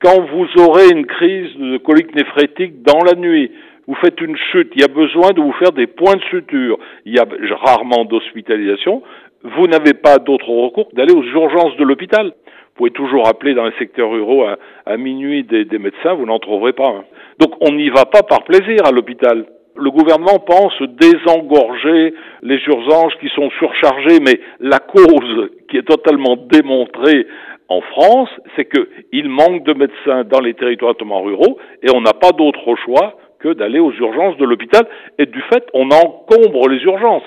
Les explications d’Yves Husson, maire de Chanaz et président de l’association.